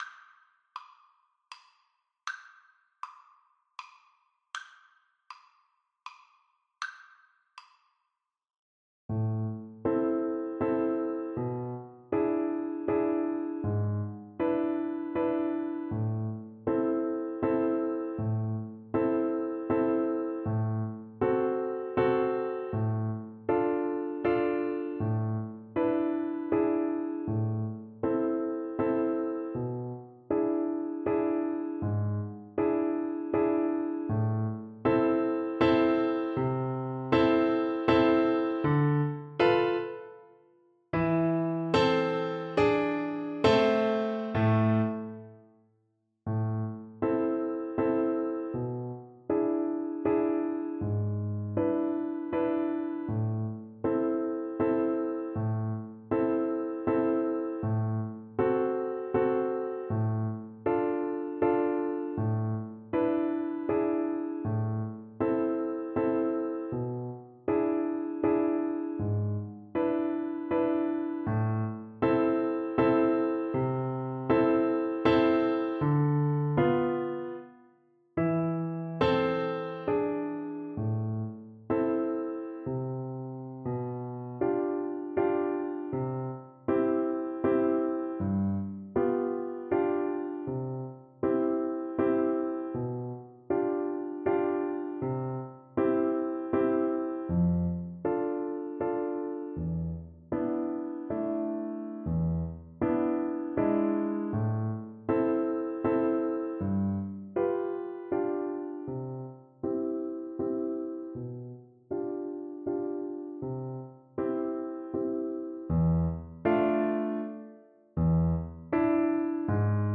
Play (or use space bar on your keyboard) Pause Music Playalong - Piano Accompaniment Playalong Band Accompaniment not yet available transpose reset tempo print settings full screen
Viola
• Piano
A minor (Sounding Pitch) (View more A minor Music for Viola )
3/4 (View more 3/4 Music)
Moderato ( = 132)
Classical (View more Classical Viola Music)